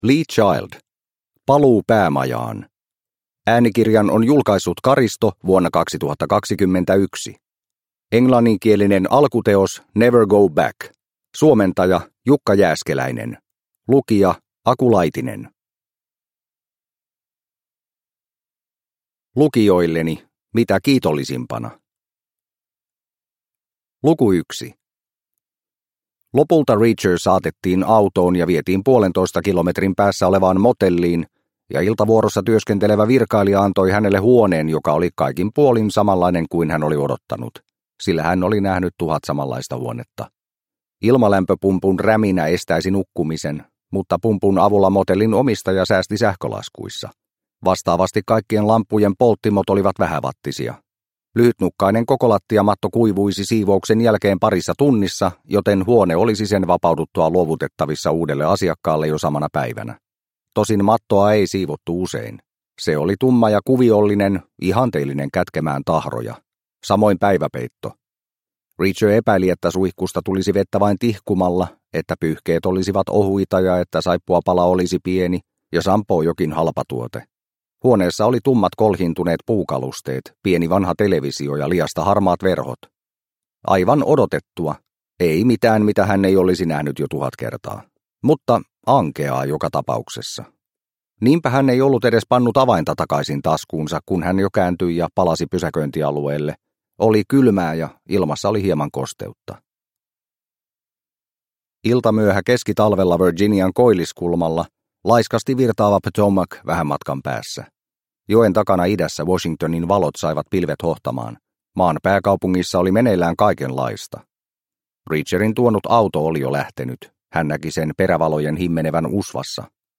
Paluu päämajaan – Ljudbok – Laddas ner